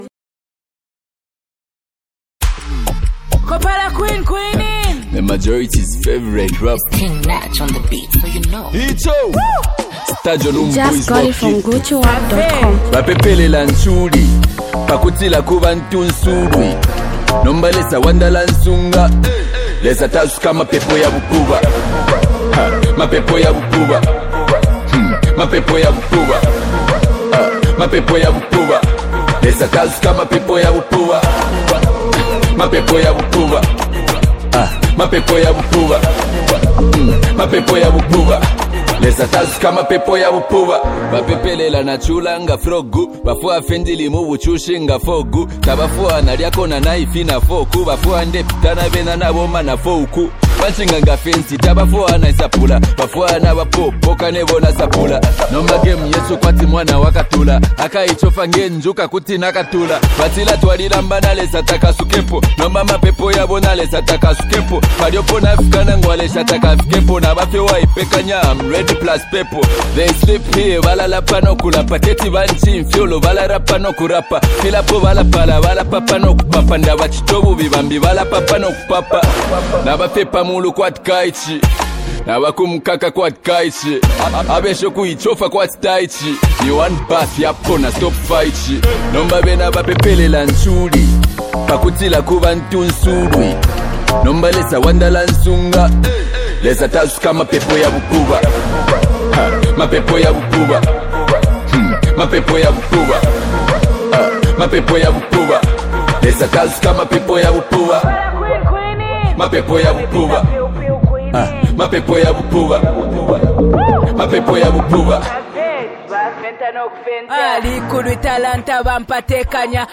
Zambian copperbelt based rap sensation artist